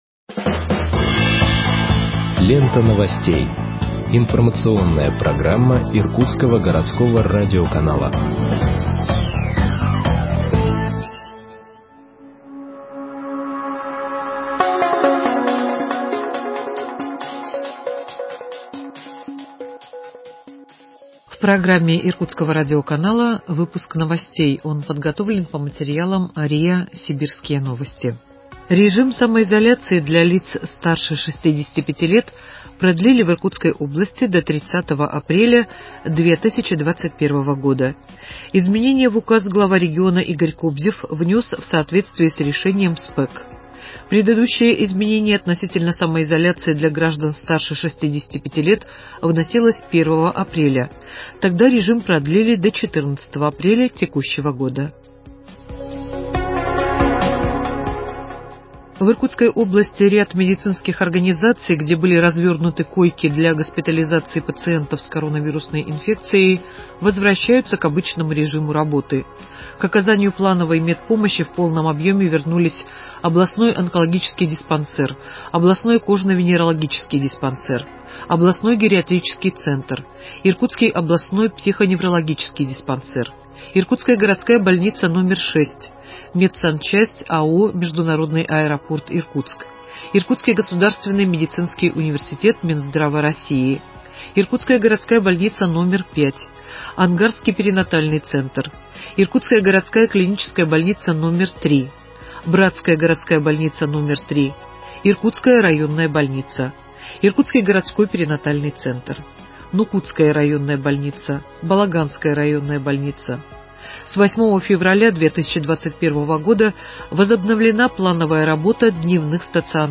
Выпуск новостей в подкастах газеты Иркутск от 16.04.2021 № 1